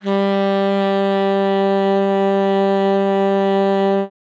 42c-sax03-g3.wav